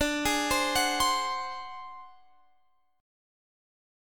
Listen to D7b5 strummed